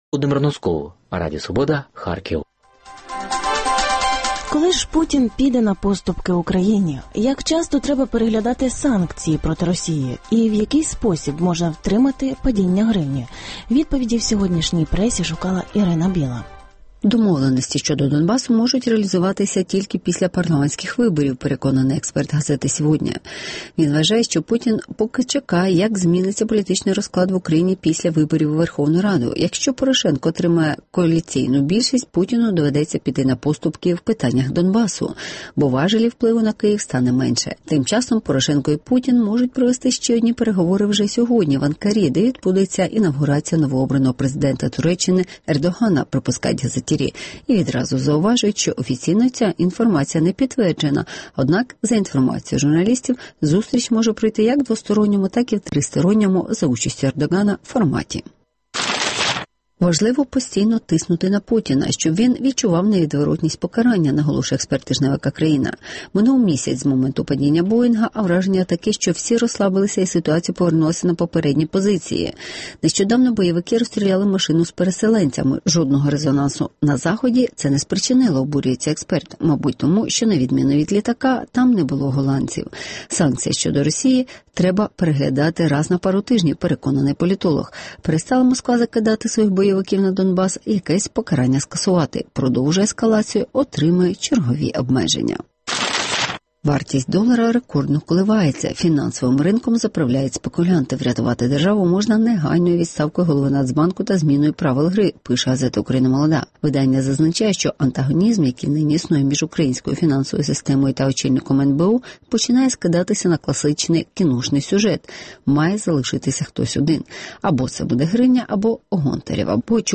Коли Путін піде на поступки Україні? (Огляд преси)